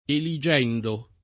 eligendo [ eli J$ ndo ]